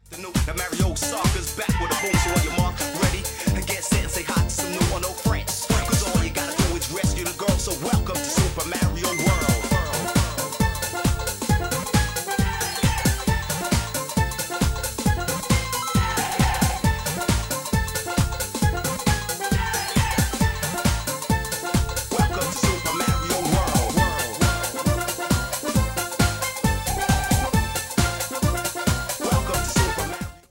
Loopback recorded preview